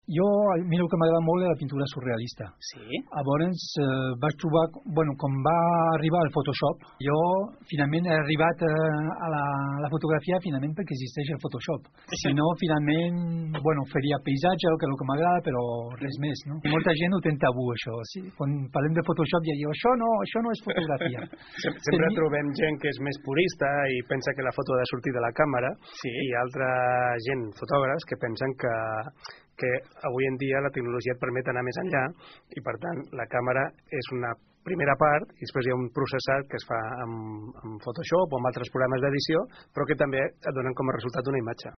Un tipus de tècnica que assegura que molts fotògrafs tenen com a tabú, tal i com també expliquen els seus propis companys de l’AFIC de Blanes.